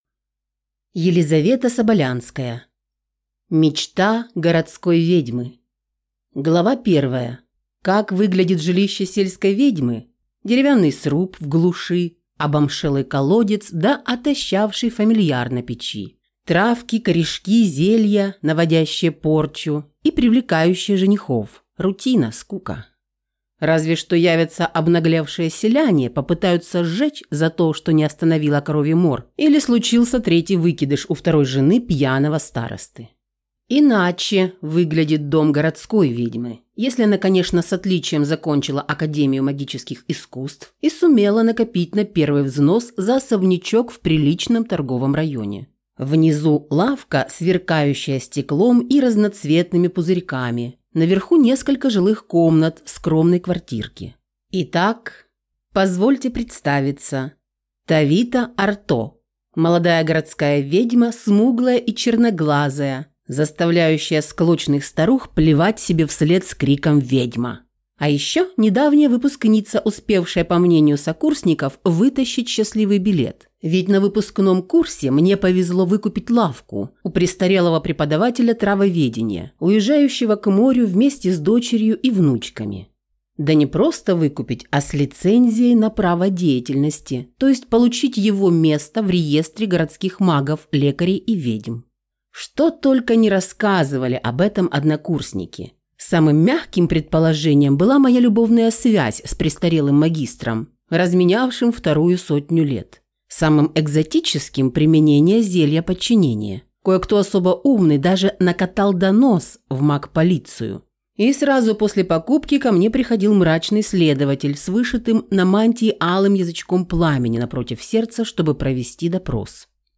Аудиокнига Мечта городской ведьмы | Библиотека аудиокниг